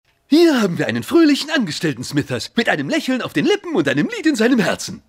Fröhlicher Angestellter.mp3